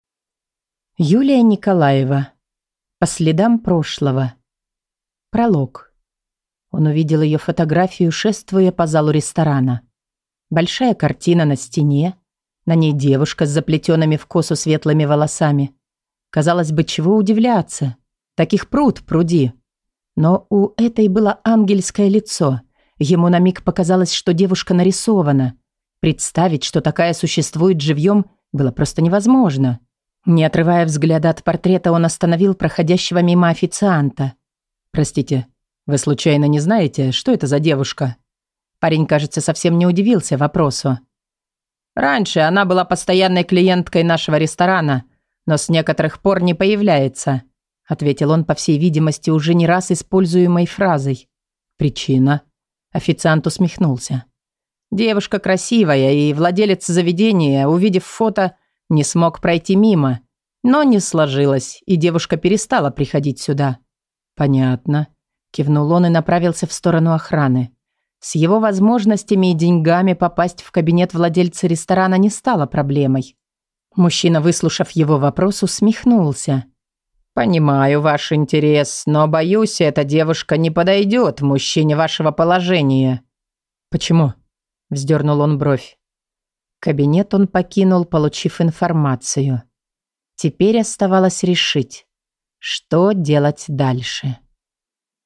Аудиокнига По следам прошлого | Библиотека аудиокниг